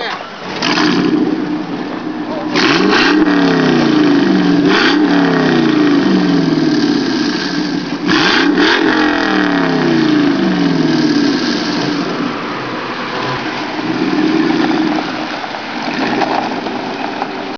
The result is that the factory muffler contributes to the mellowing of the sound, adding a bit of low frequency response to what would have been a raspy exhaust note.
Nonetheless, the rest of the RPM range above 1500 sounds heavenly whether on the throttle or off, as shown in the following sound files:
: startup and revving, taped outside the car